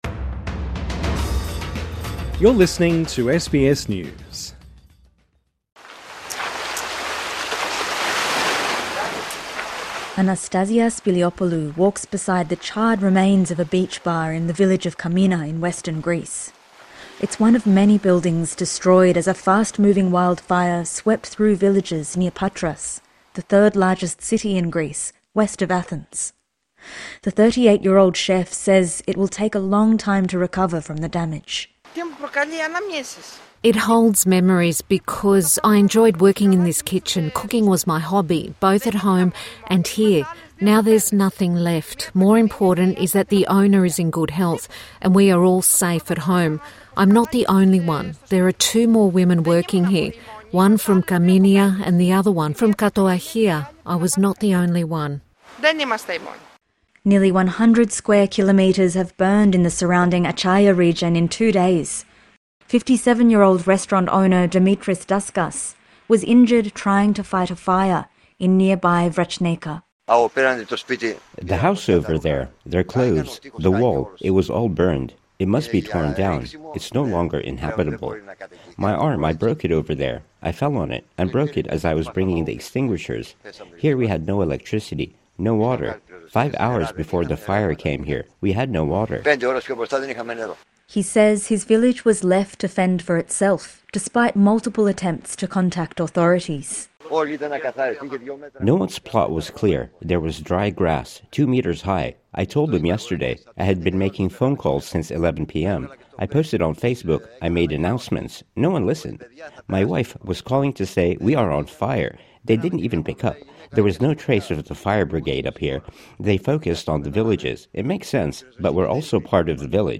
(Sounds of fire crackling)